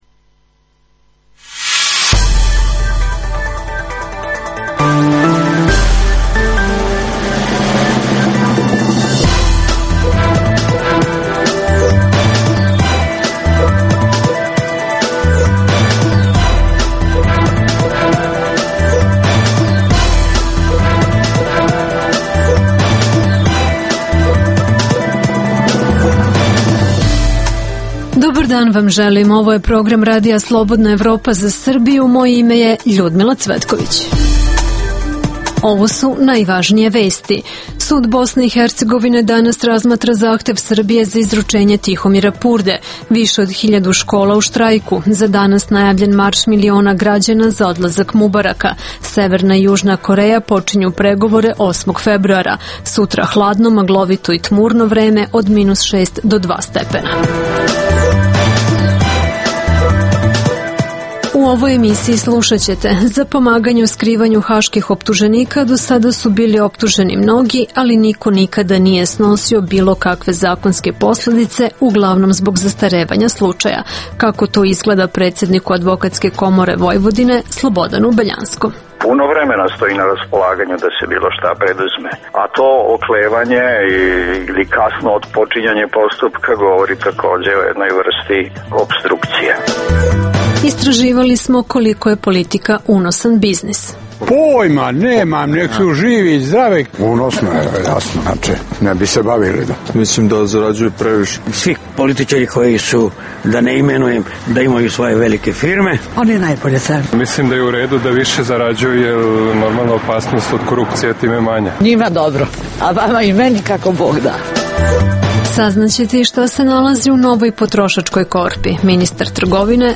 - Reporter RSE na novinarskoj projekciji filma “Nekad braća” o prekinutom prijateljstvu Vlada Divca i Dražena Petrovića.